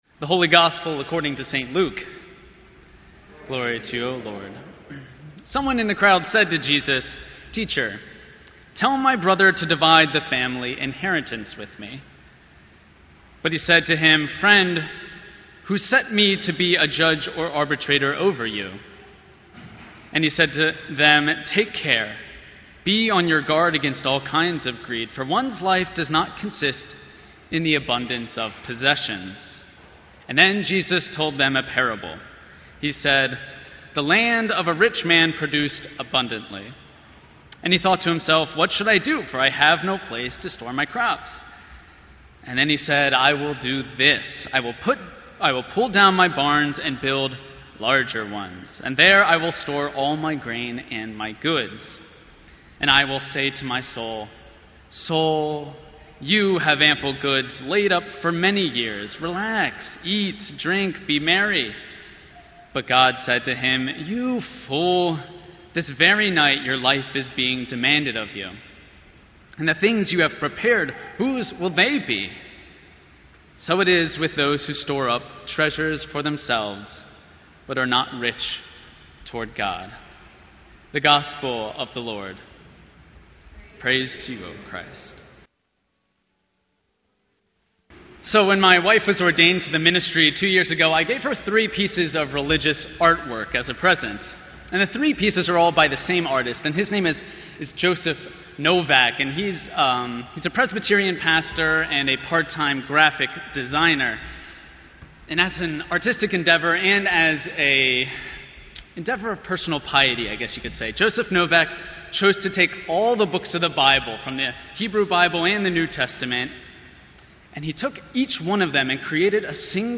Sermon_7_31_16.mp3